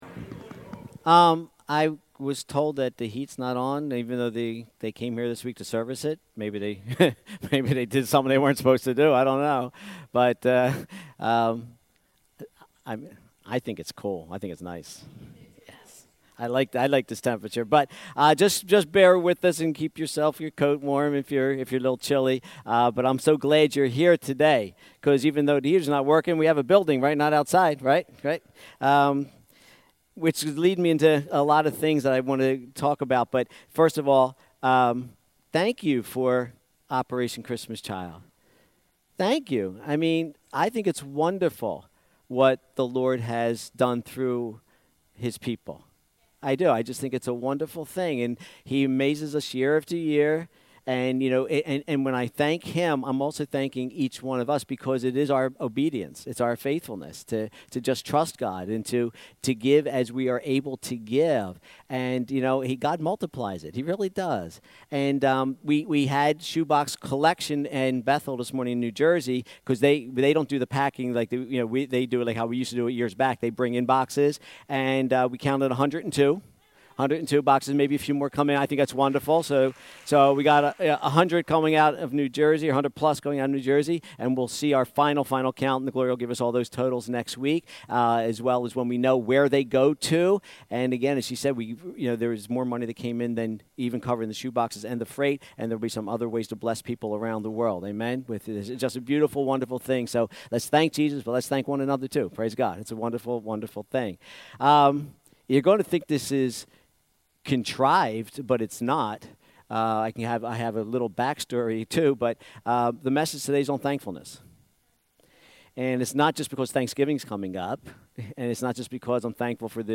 Sermons | First Christian Assembly